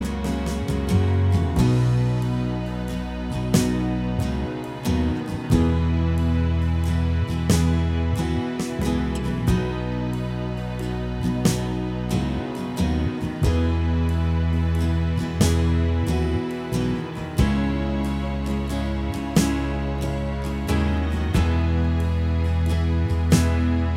Minus Electric Guitar Soft Rock 4:46 Buy £1.50